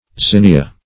Zinnia \Zin"ni*a\, n. [NL.